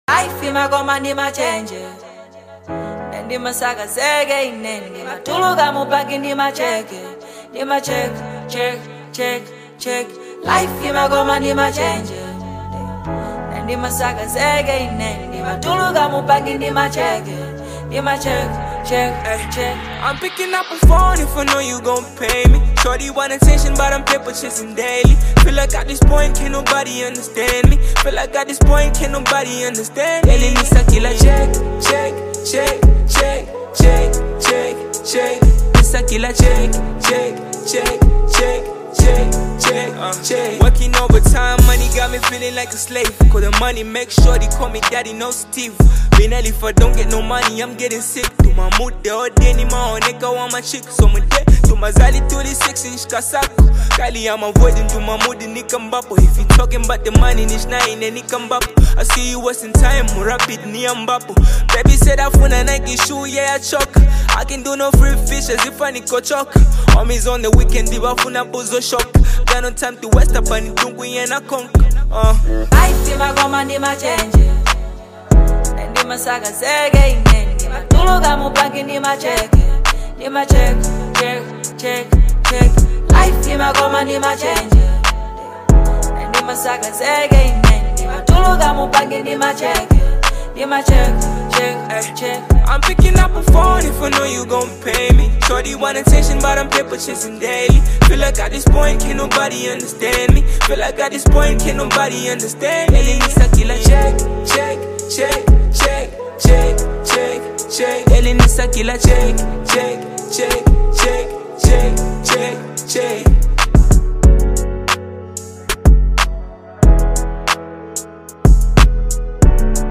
is a high-energy track
With catchy rhythms and sharp lyrics